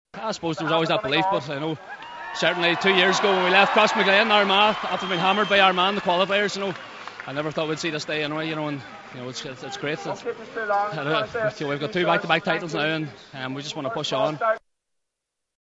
Defender Karl Lacey was asked if ever thought Donegal could win back-to-back titles: